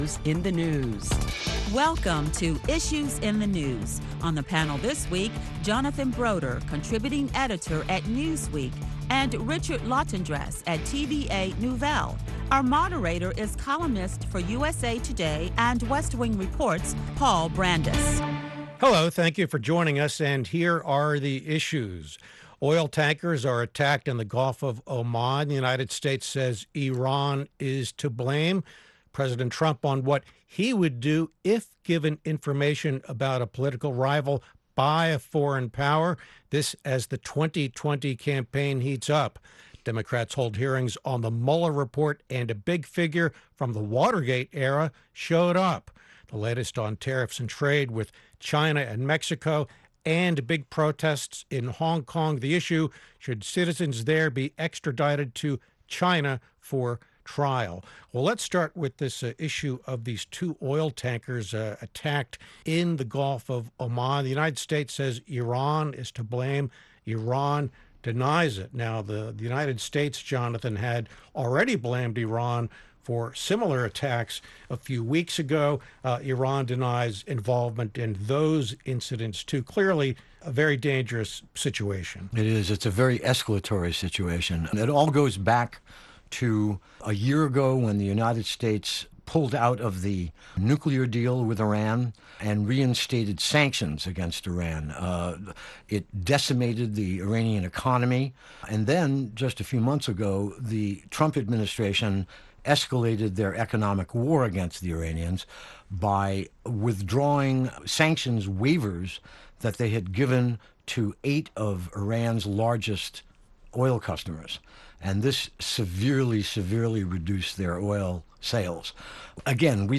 Listen to a panel of leading Washington journalists as they discuss the week's headlines beginning with the recent oil tanker attack in the Gulf of Oman, and why the U.S. says Iran is to blame.